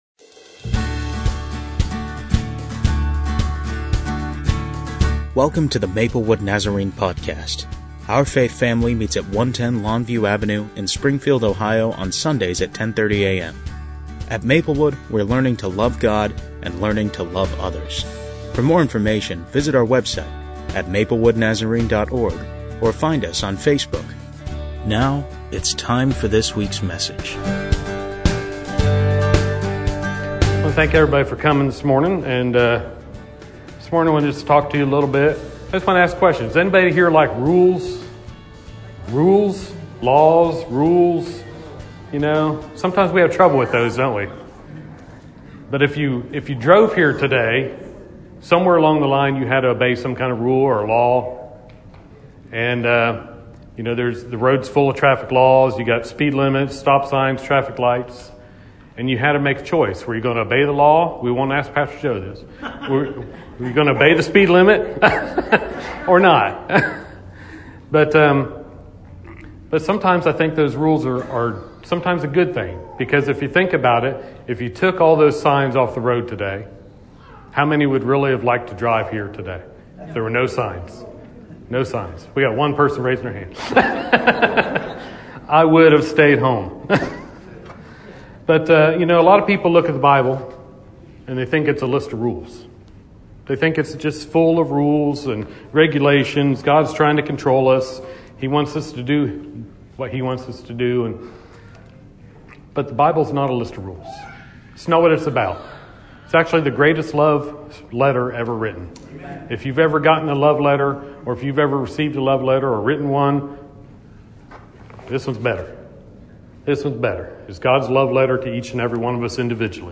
a challenging message for people who call themselves Christians